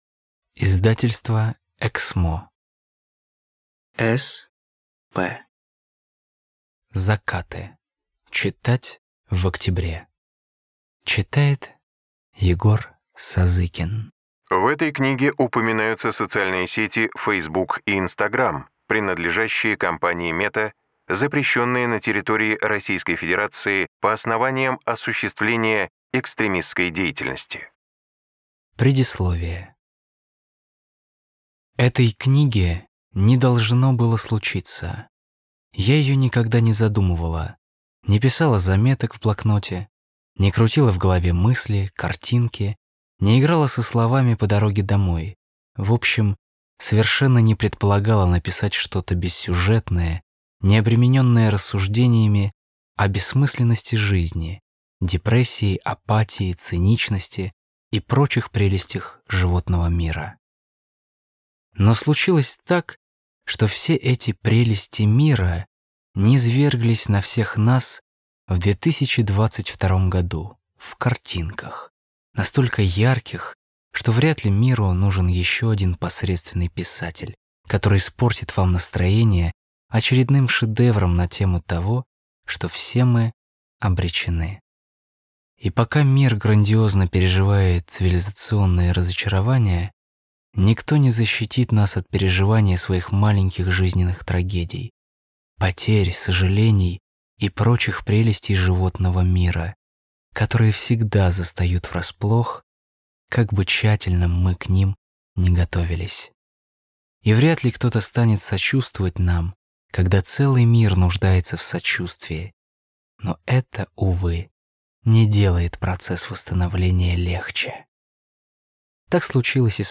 Аудиокнига Закаты (читать в октябре) | Библиотека аудиокниг